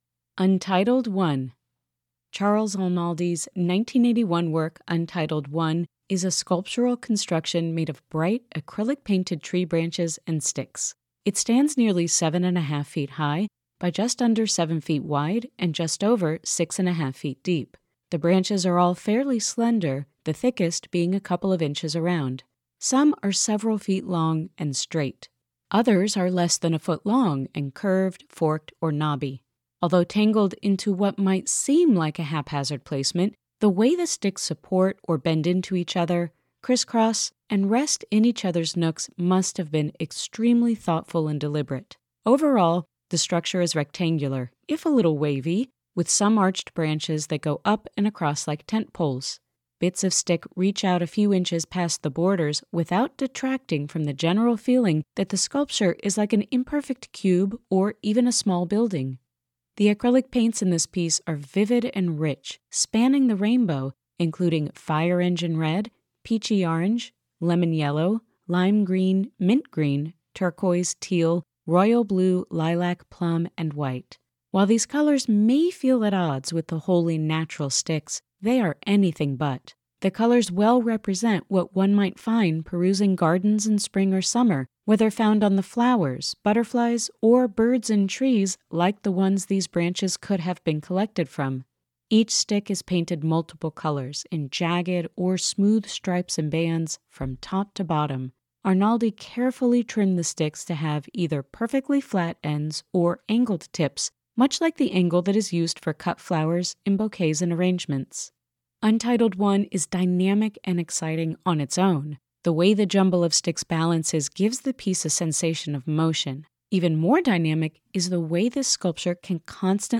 Audio Description (02:26)